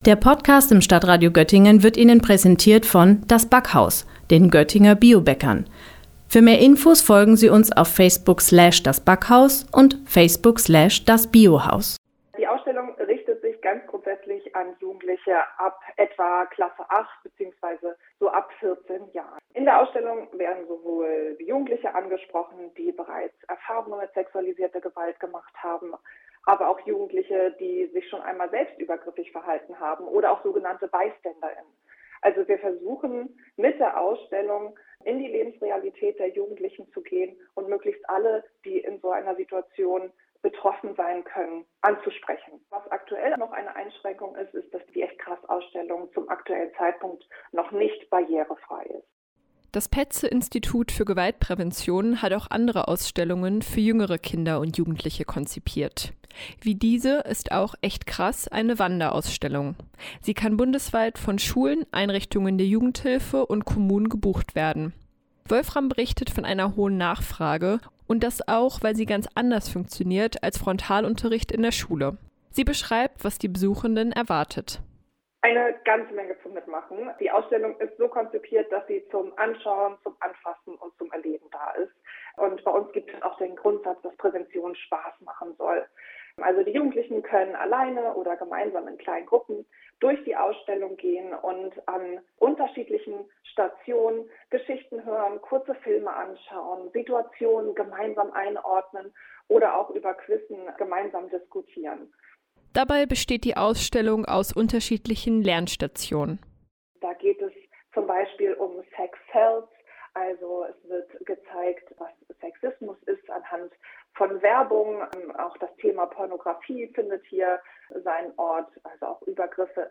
Beiträge > Sexuelle Gewalt unter Jugendlichen – Ausstellung „Echt krass“ gastiert in Northeim - StadtRadio Göttingen